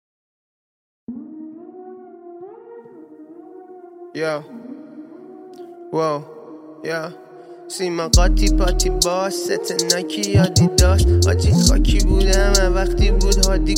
دانلود آهنگ های جدید رپ فارسی های جدید